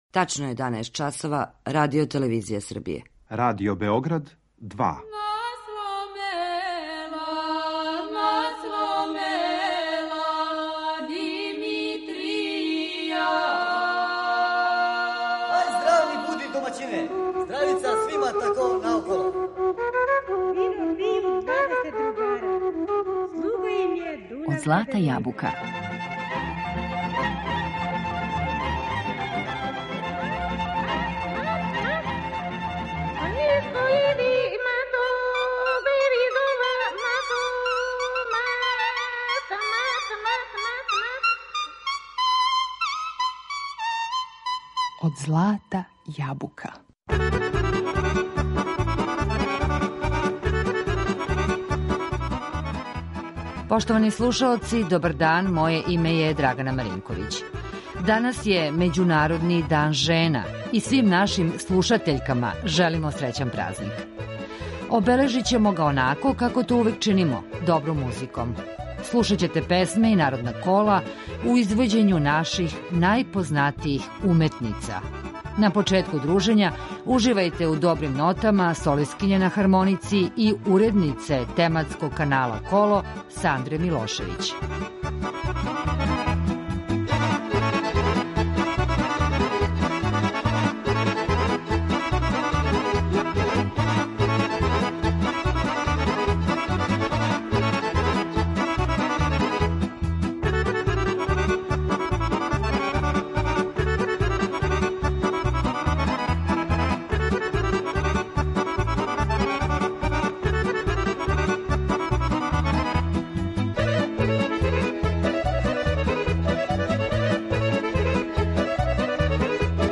У звуцима фолклора аутентичне традиционалне музике уживаћете у извођењима солисткиња различитих генерација.